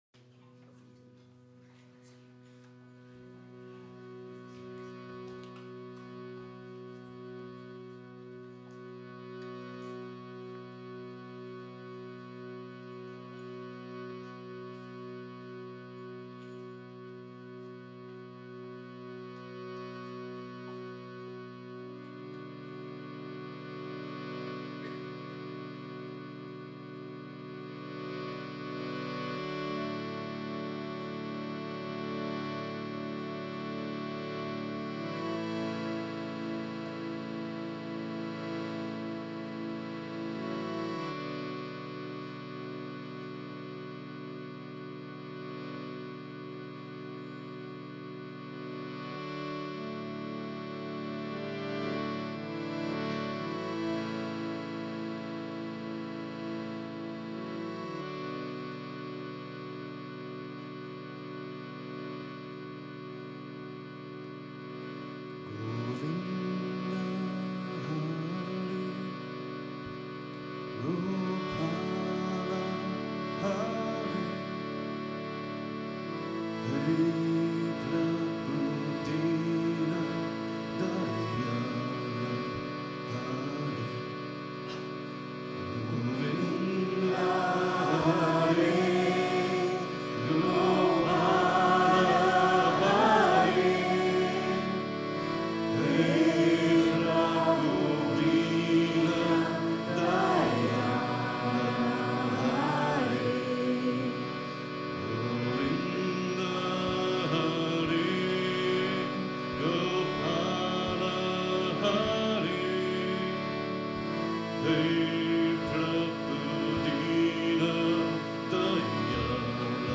Krishna Kirtan and Mantra Chanting
Radhe Govinda live im Satsang
Live Recordings from Yoga Vidya Ashram Germany.